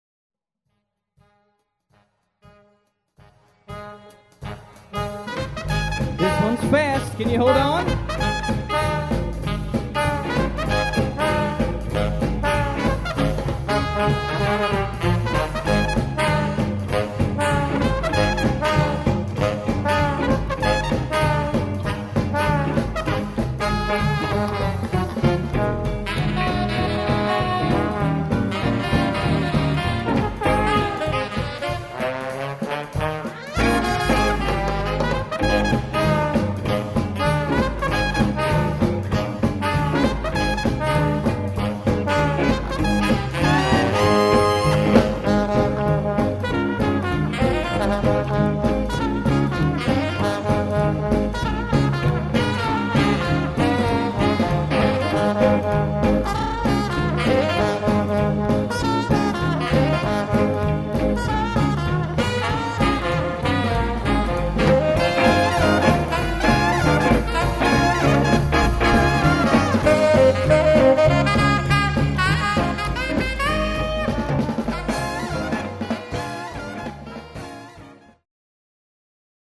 Live Demo Sampler